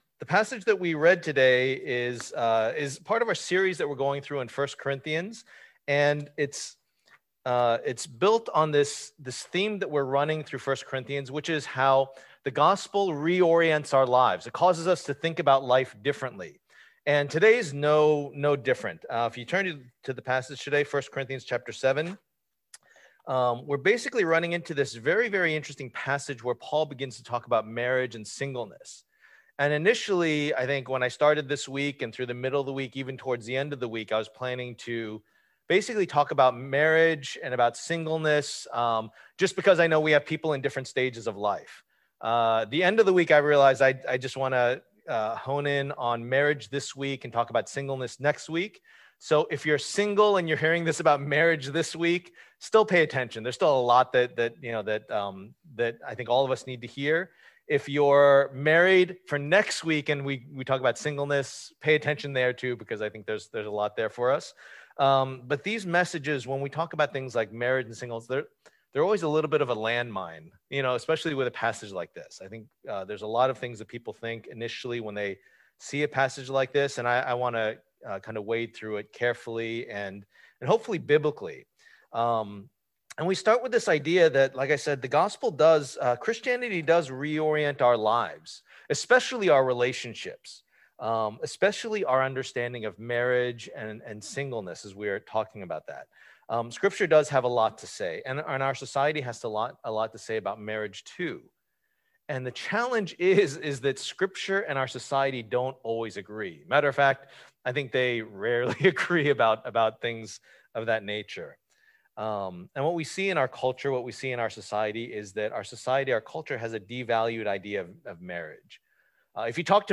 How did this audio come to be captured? Service Type: Lord's Day